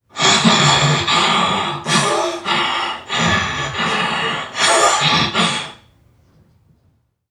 NPC_Creatures_Vocalisations_Robothead [65].wav